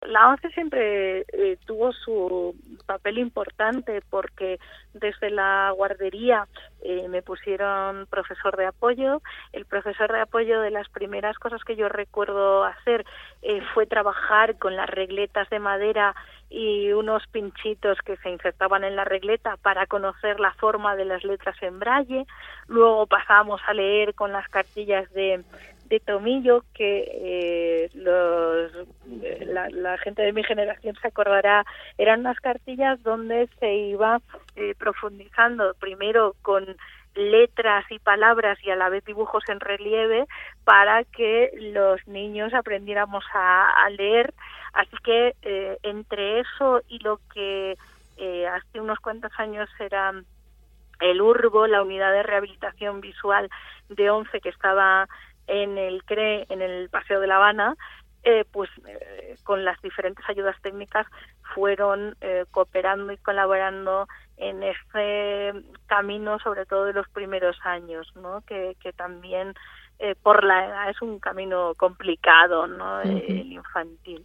rememora formato MP3 audio(1,42 MB) y su voz brilla.